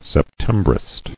[Sep·tem·brist]